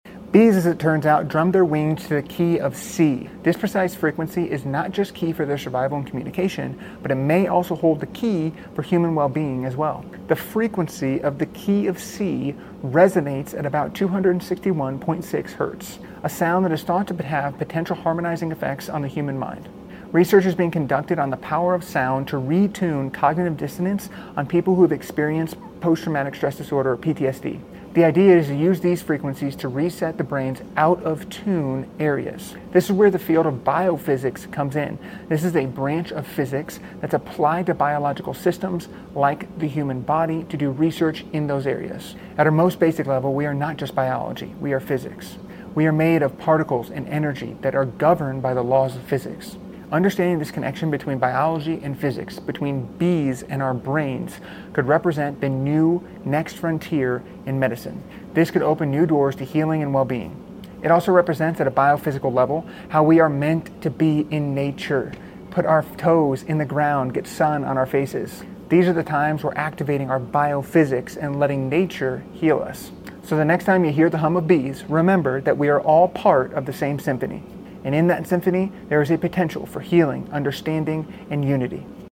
Bees hum in the key sound effects free download
Bees hum in the key of C, a sound that might hold healing power for our minds.